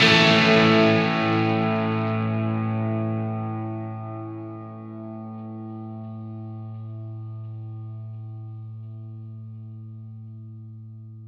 Indie Pop Guitar Ending 02.wav